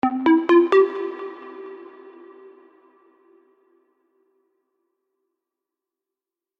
A Congratulatory Sound effect with Sine Tone.
Happy